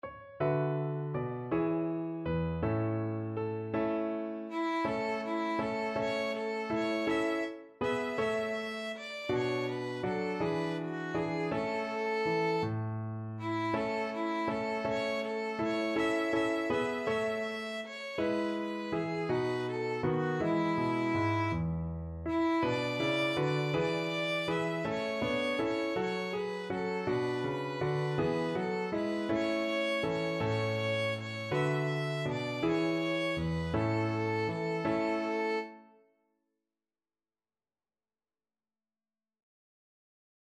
One in a bar .=c.54
3/4 (View more 3/4 Music)